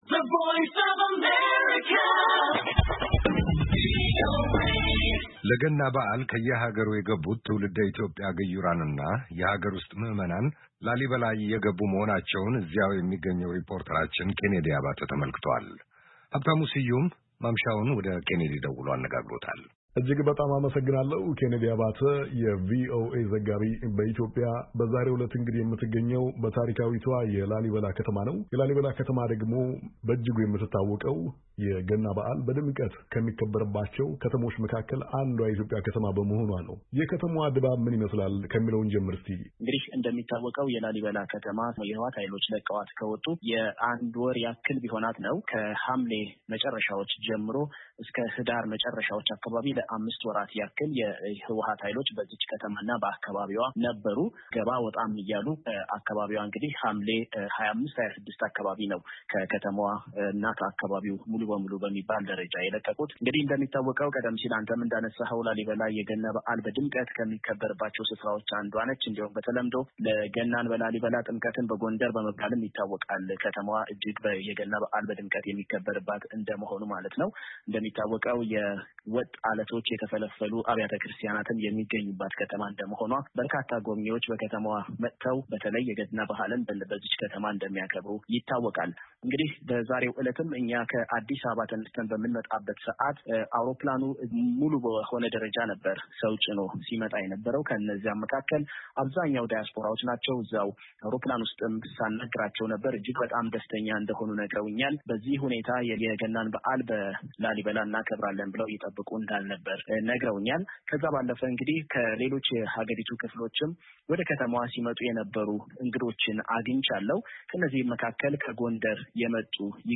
ዋሽንግተን ዲሲ እና ላሊበላ —